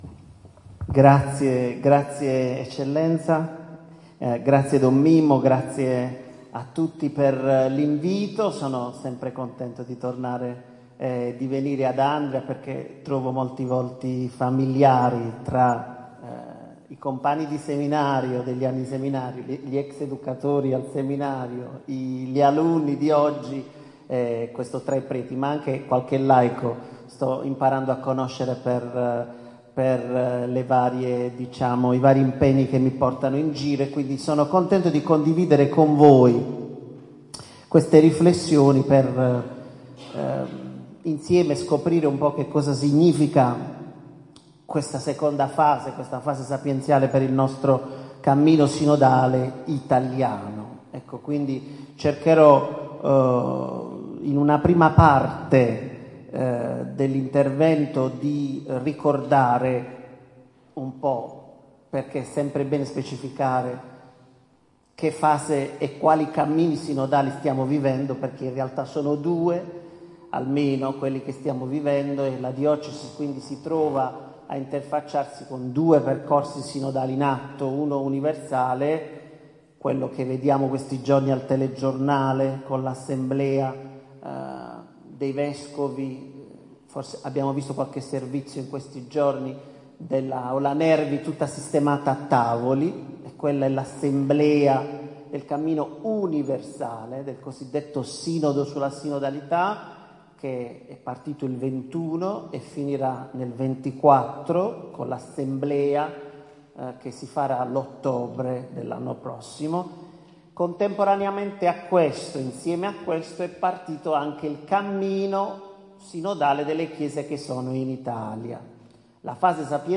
Intervento